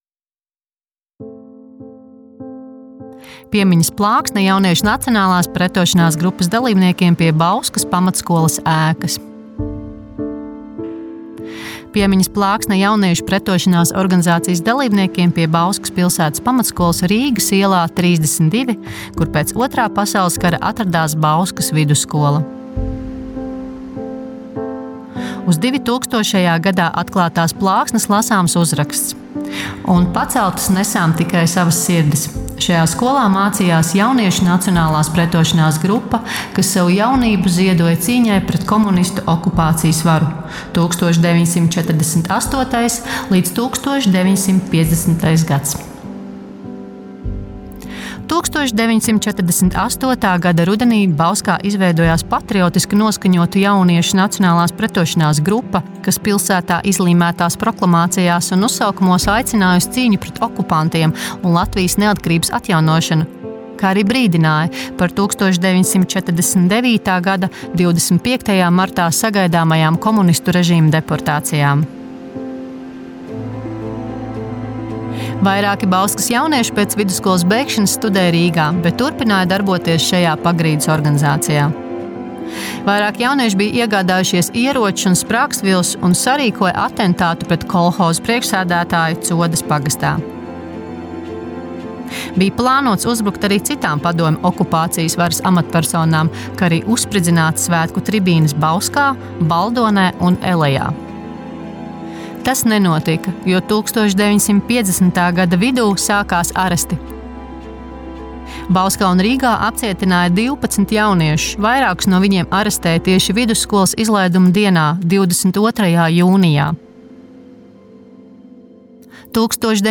AUDIO STĀSTĪJUMS